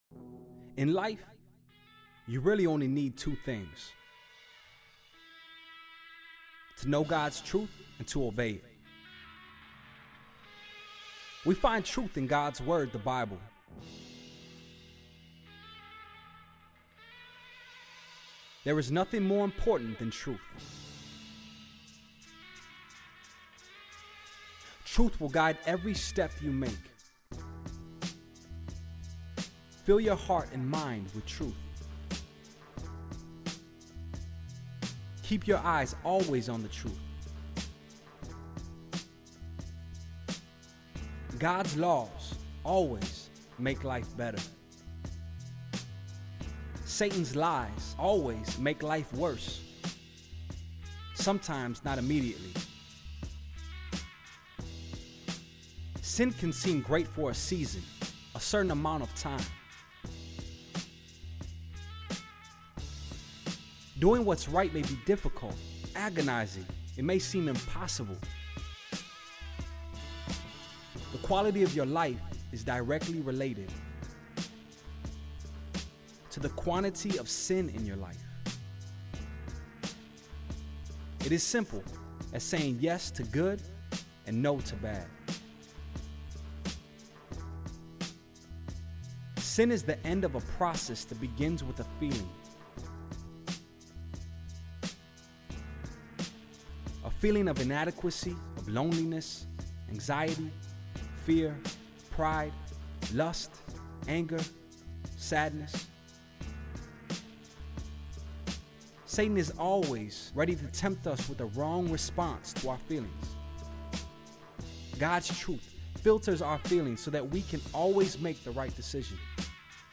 Hope Speaks ESL Audio Lesson 23 Age Teen, Teen-Lower, Teen-Upper, Adult Program Hope Speaks ESL Type Audio Region Global Country Language English Download Play Created by OneHope, the Hope Speaks ESL Audio includes 23 files that feature Scripture passages that directly correspond to the Topic of each lesson in the Teacher’s Guide and Student Workbook.